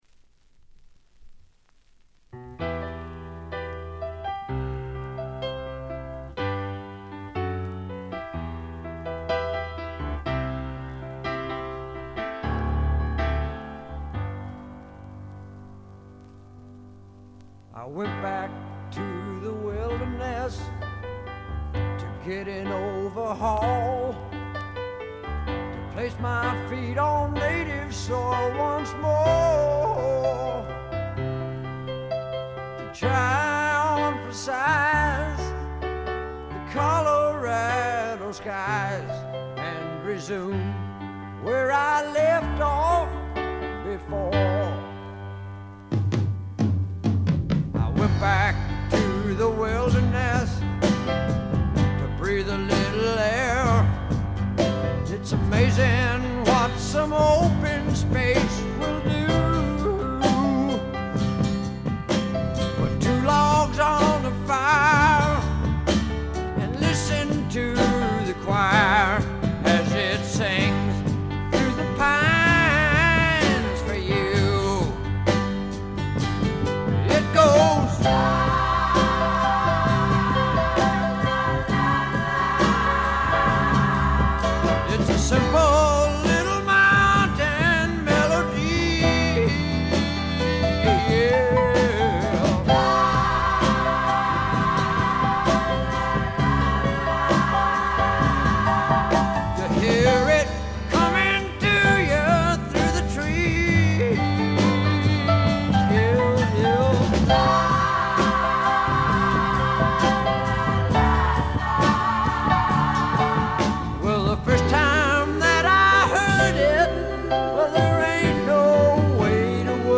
ほとんどノイズ感無し。
試聴曲は現品からの取り込み音源です。
Guitar and Vocals